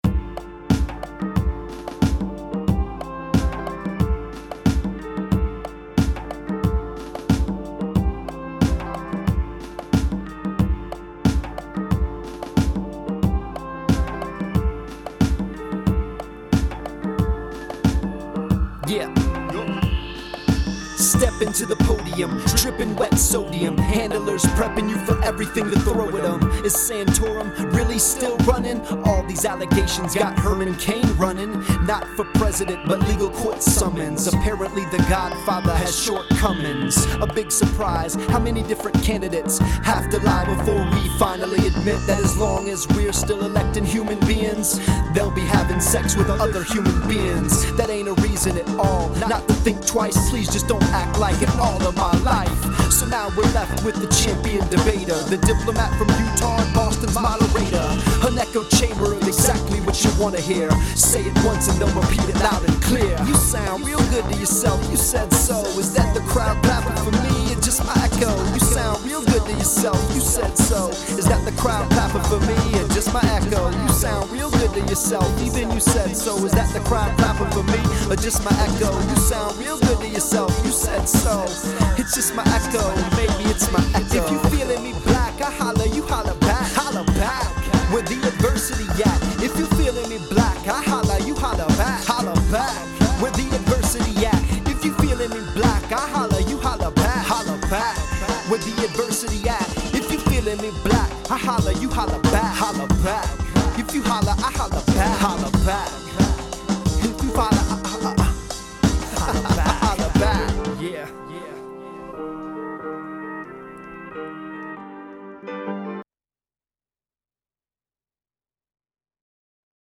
Mostly because this is a non-partisan site and it’s too easy to be misinterpreted as partial one way or the other in a 90 second rap song.